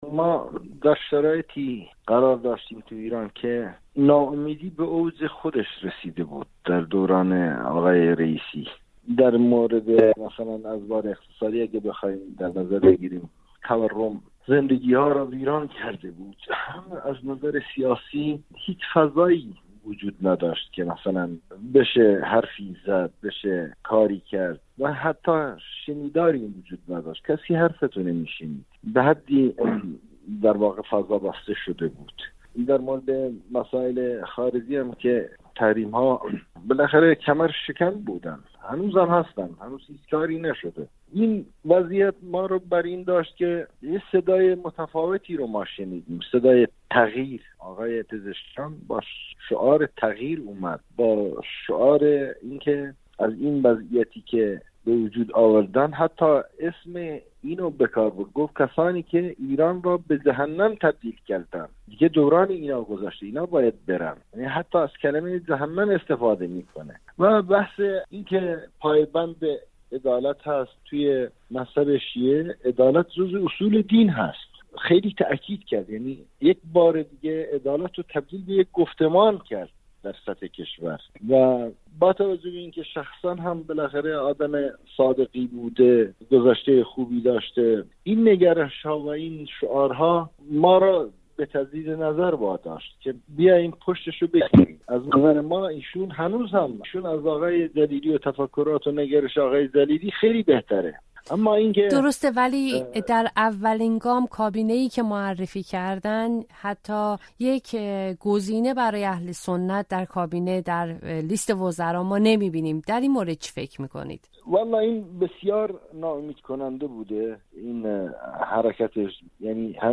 گفت‌وگو با حاصل داسه؛ چرا پزشکیان وزیر اهل سنت معرفی نکرد؟